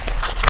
pr_tome_open.wav